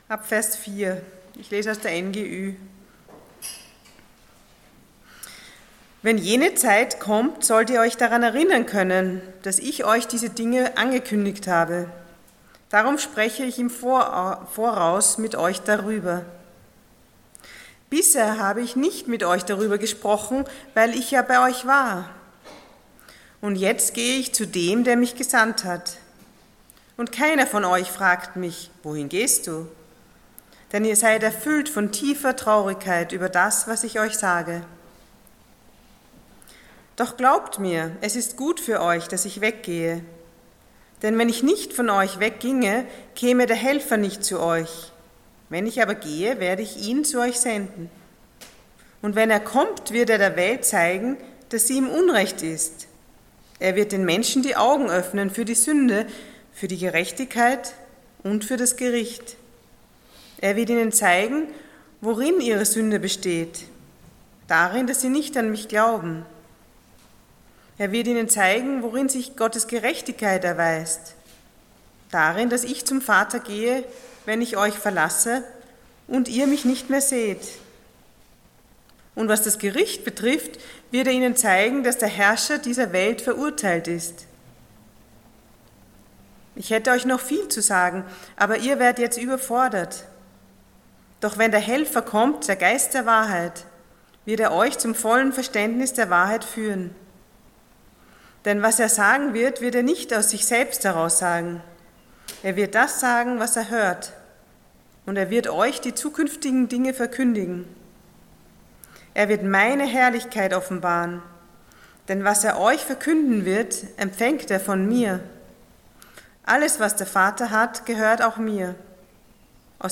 John 16:4-4:15 Dienstart: Sonntag Morgen %todo_render% Wozu der Heilige Geist?